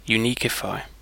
Ääntäminen
Ääntäminen UK Haettu sana löytyi näillä lähdekielillä: englanti Käännöksiä ei löytynyt valitulle kohdekielelle.